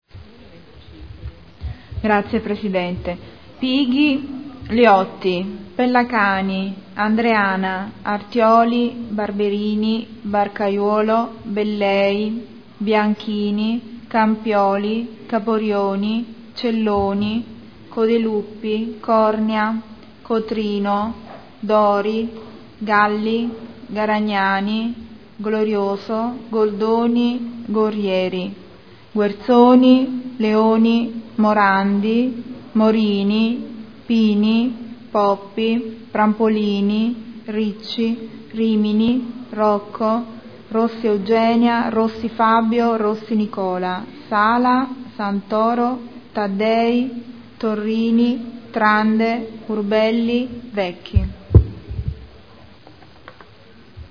Seduta del 26/11/2012. Appello.